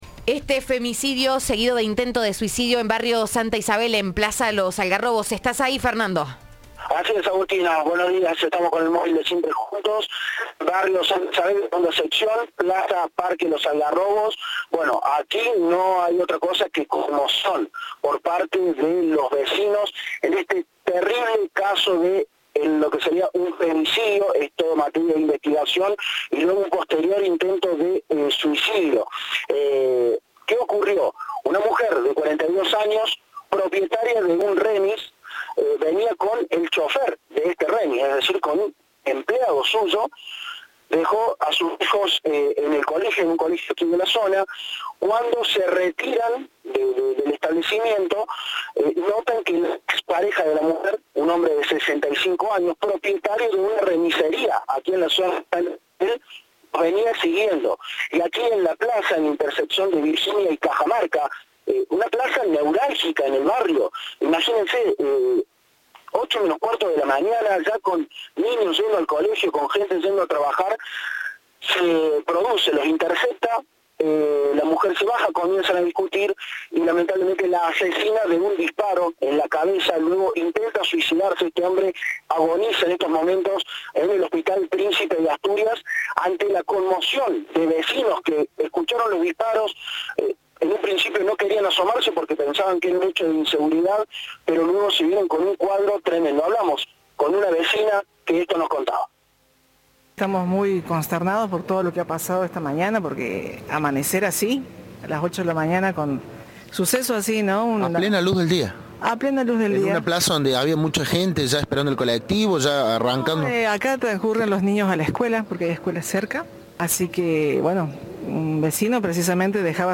Lo indicó a Cadena 3 una vecina del hecho que conmociona a toda la ciudad.